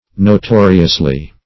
[1913 Webster] -- No*to"ri*ous*ly, adv. --